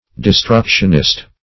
Search Result for " destructionist" : The Collaborative International Dictionary of English v.0.48: Destructionist \De*struc"tion*ist\, n. 1.
destructionist.mp3